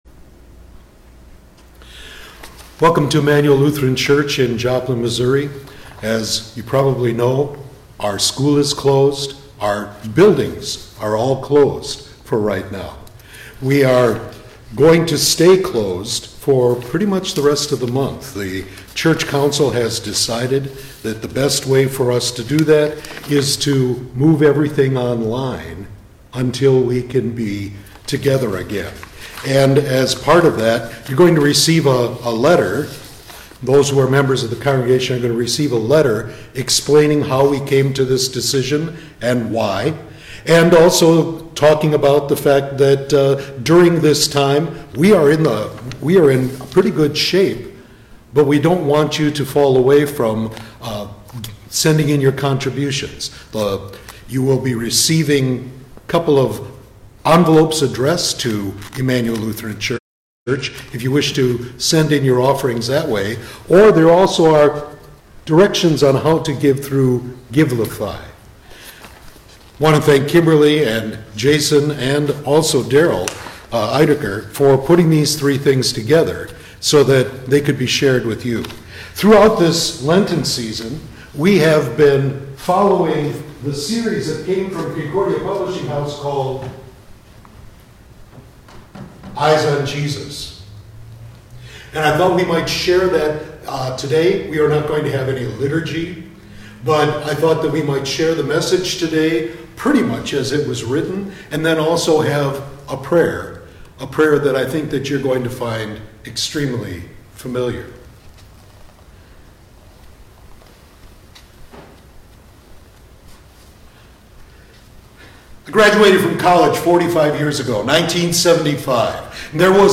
Sermon for Lent Midweek 3